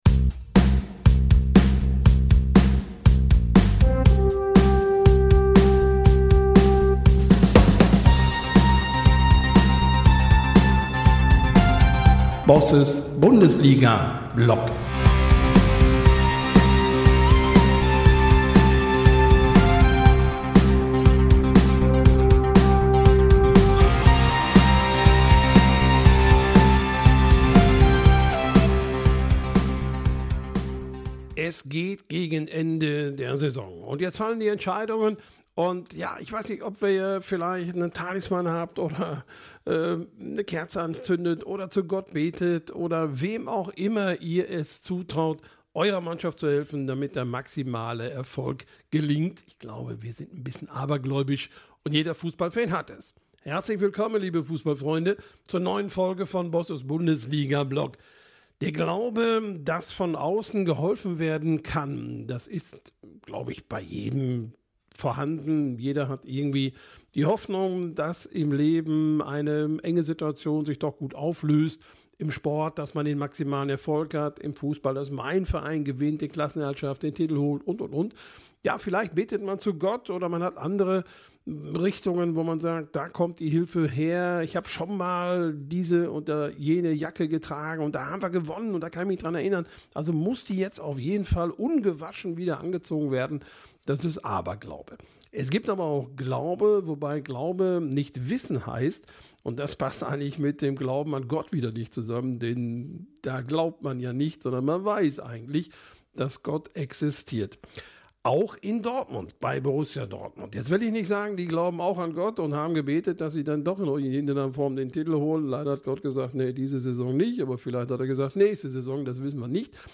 Mich interessiert, was sie antreibt, welche Menschen sie erreichen wollen und welche Erfahrungen sie auf diesem Weg bereits gemacht haben. Wir reden offen über Herausforderungen, Zweifel und echte Lernmomente, aber auch über Hoffnung, Mut und die Kraft von Gemeinschaft.